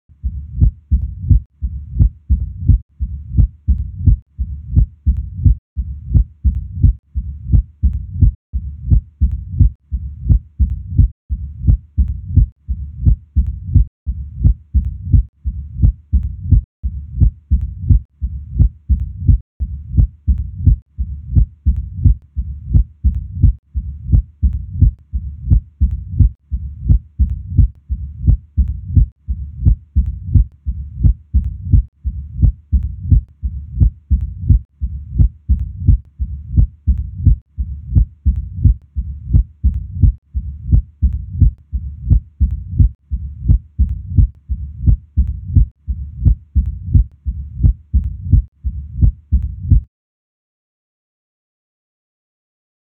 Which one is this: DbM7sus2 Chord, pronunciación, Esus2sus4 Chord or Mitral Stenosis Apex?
Mitral Stenosis Apex